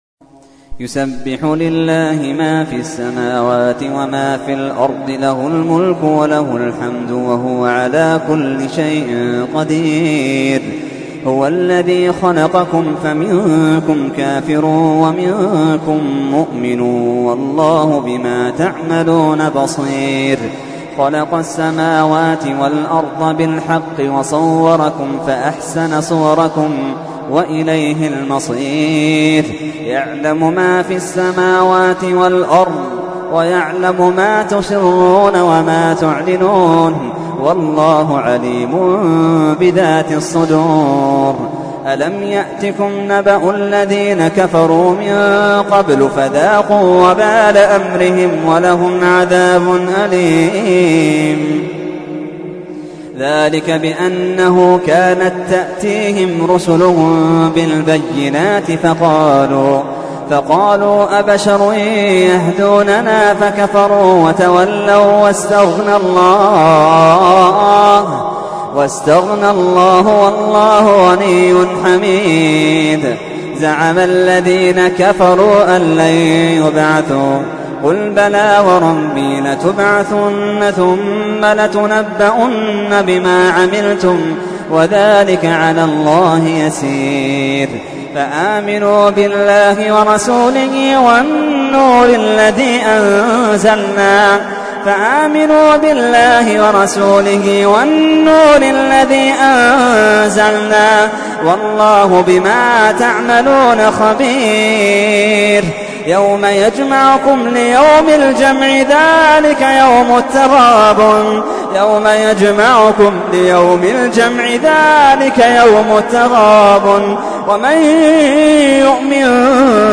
تحميل : 64. سورة التغابن / القارئ محمد اللحيدان / القرآن الكريم / موقع يا حسين